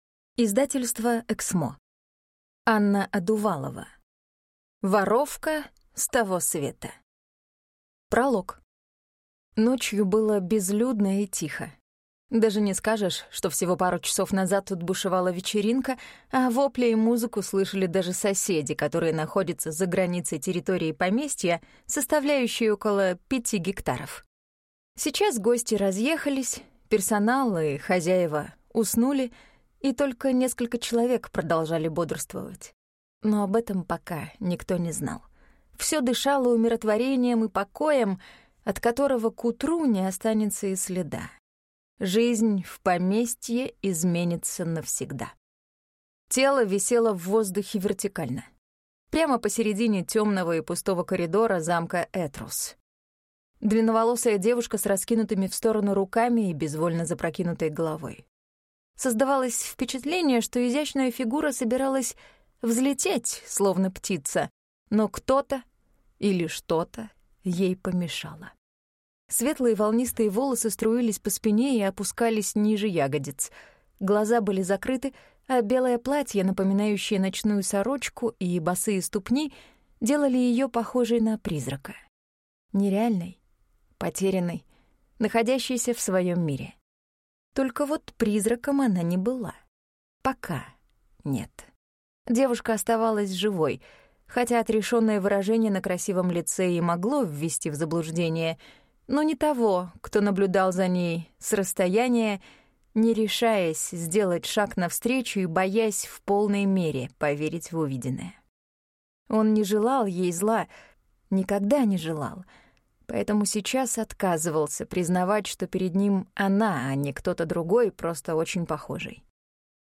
Аудиокниги про попаданцев